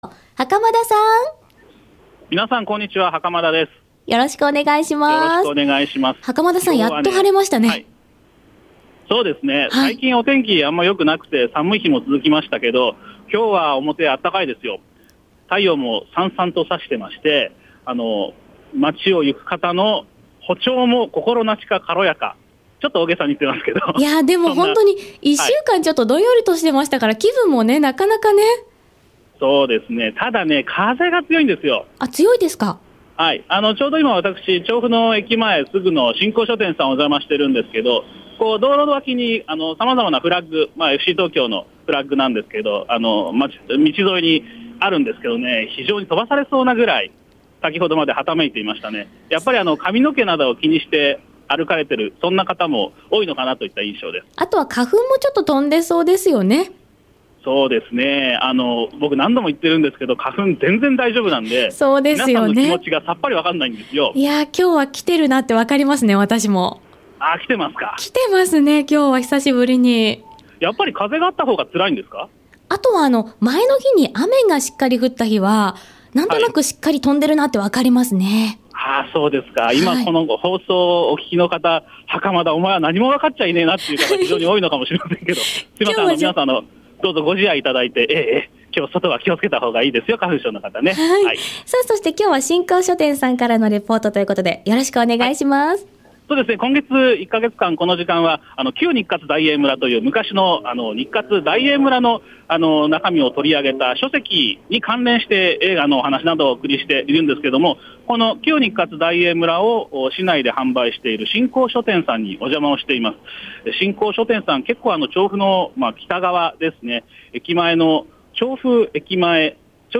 書籍、旧日活・大映村に関連するレポートを連続でお送りしておりますが、 その本を販売しているお店の一つ、となります。 午後のカフェテラス（火）街角レポート_16_03_15 屋外のインタビューにお答えいただき、本当に恐縮です。